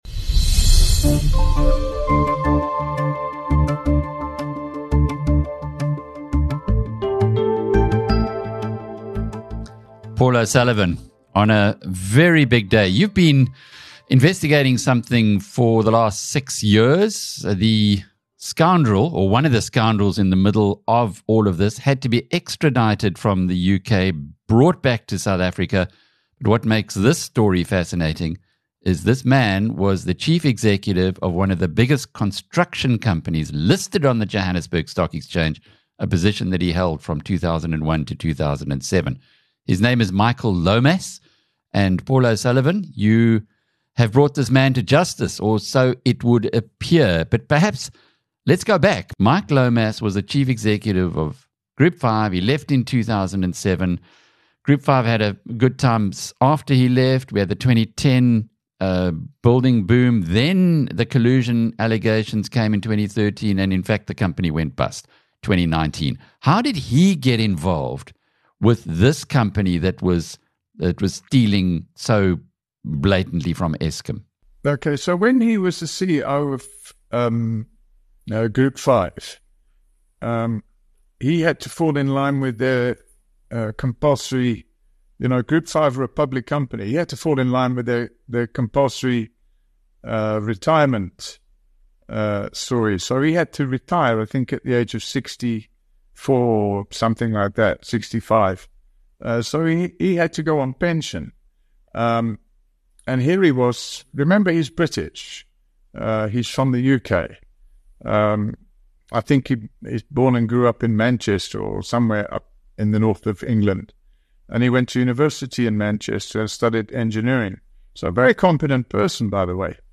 O’Sullivan spoke to BizNews editor Alec Hogg.